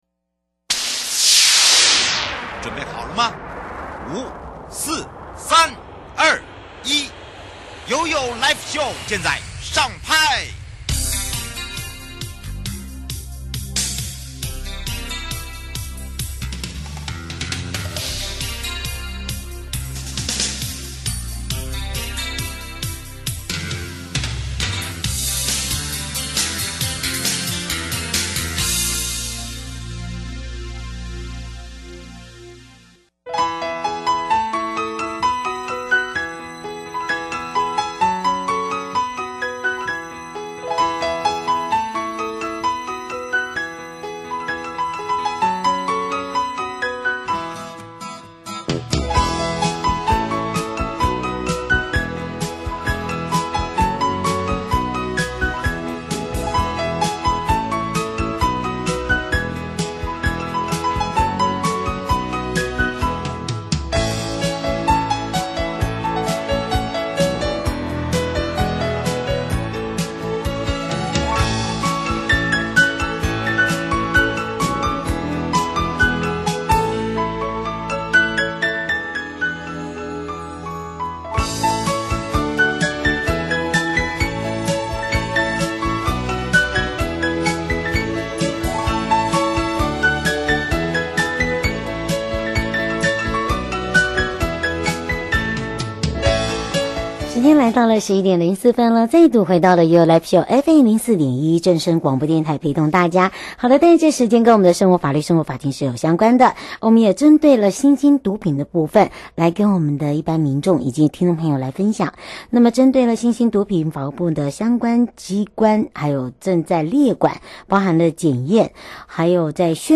受訪者： 1. 台北地檢 蕭奕弘檢察官 2. 矯正署 黃俊棠署長 節目內容： 1. 為什麼關不滿刑期就可以出來